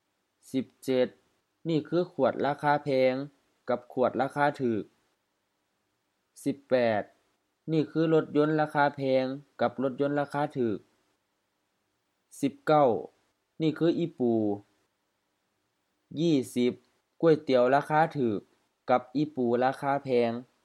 Notes: pronunciation: often with rising tones, especially in isolation